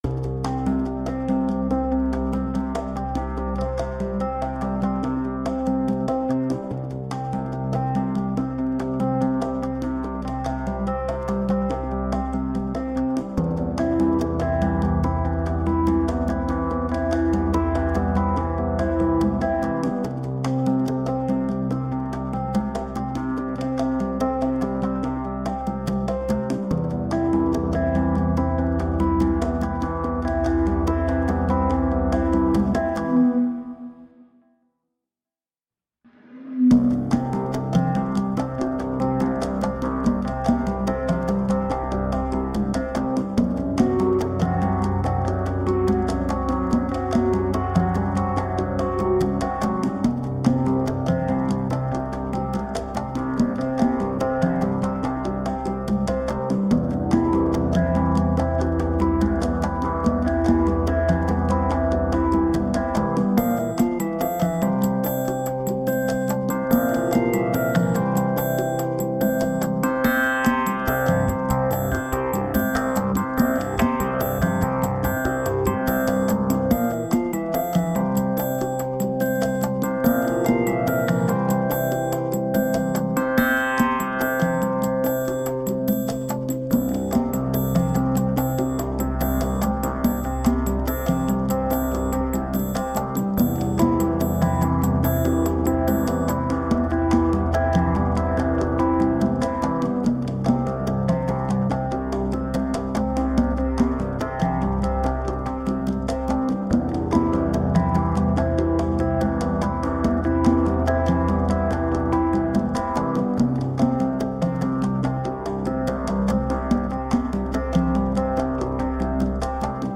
Underwater electronic soundscapes.
Tagged as: Ambient, Electronica, Background Mix, Space Music